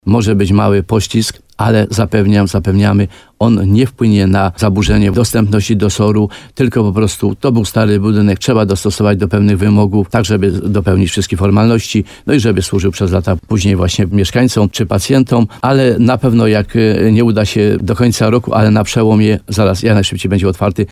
Może być poślizg w oddaniu do użytku Szpitalnego Oddziału Ratunkowego w Limanowej po przebudowie - informuje starosta limanowski Mieczysław Uryga.